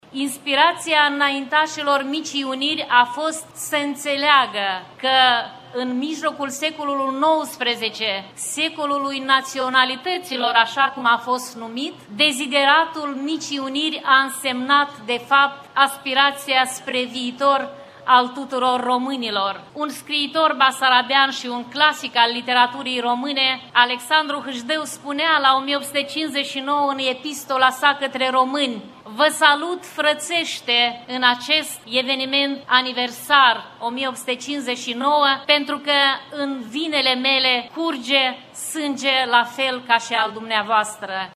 Aproximativ 5.000 de persoane au participat, astăzi, în Piaţa Unirii din Iaşi la manifestările organizate pentru a marca importanţa Unirii de la 1859.
Ministrul Culturii din Republica Moldova, Monica Babuc a exprimat, în numele guvernului de la Chişinău, speranţa unui parcurs comun european pentru cele două ţări care să răspundă aspiraţiilor cetăţenilor Republicii Moldova: